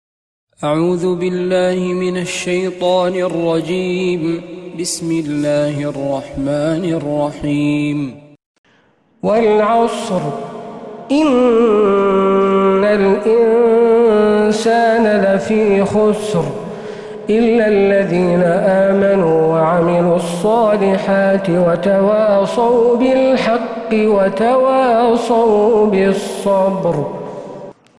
تلاوات الحرمين